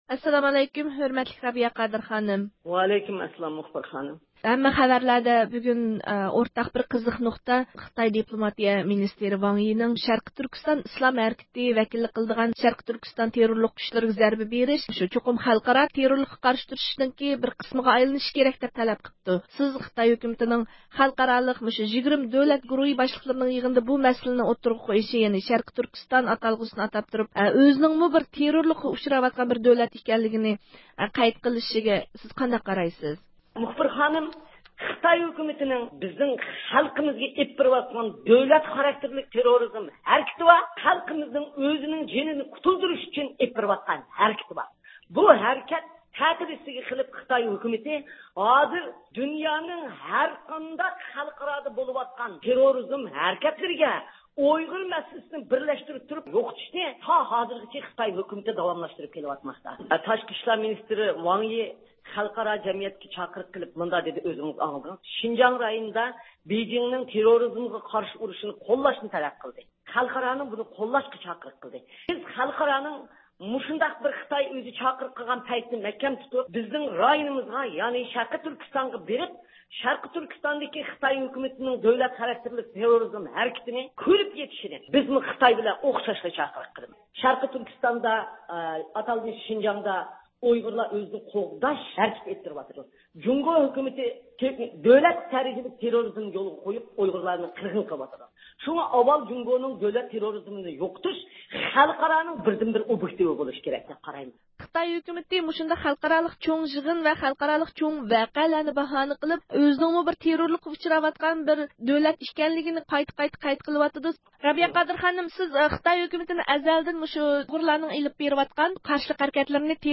دۇنيا ئۇيغۇر قۇرۇلتىيى رەئىسى رابىيە قادىر خانىم بۈگۈن رادىيومىز مۇخبىرىنىڭ زىيارىتىنى قوبۇل قىلىپ، ۋاڭ يىنىڭ سۆزلىرىگە ئىنكاس قايتۇردى.